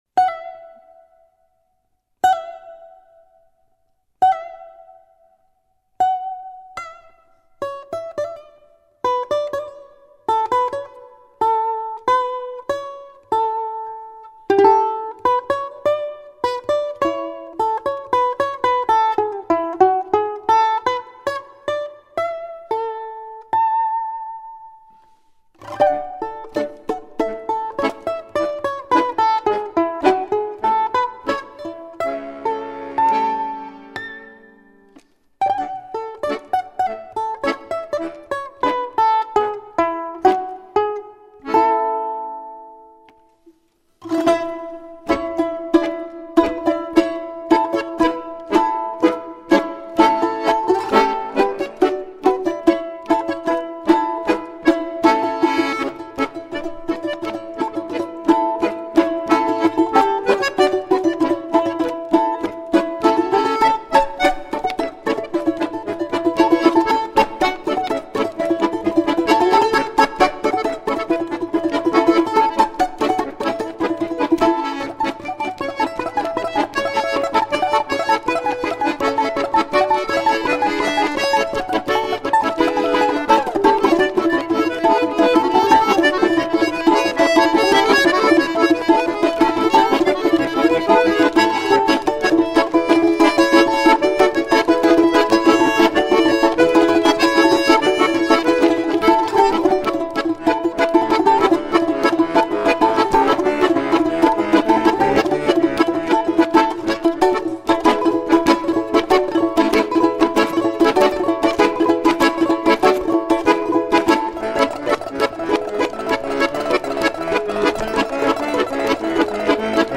Камаринская - русская народная песня. Мелодия песни Камаринская.